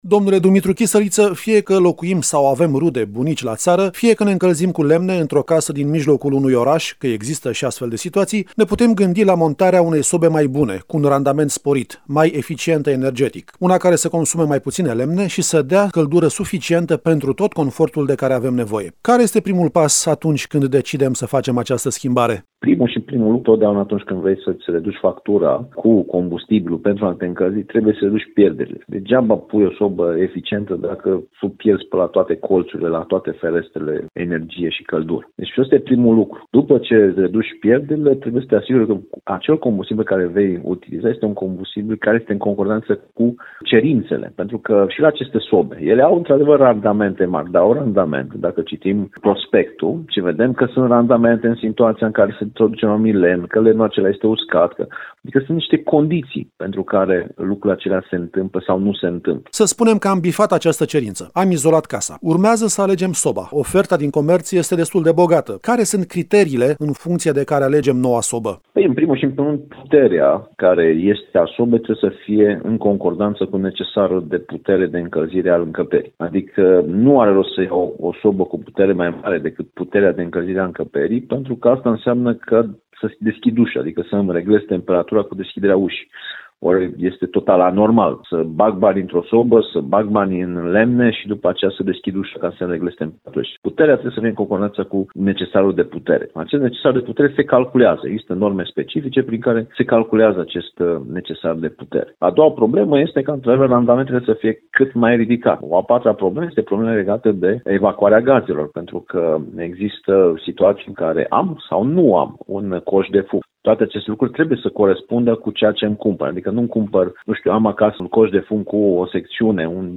Răspunsurile, în interviul următor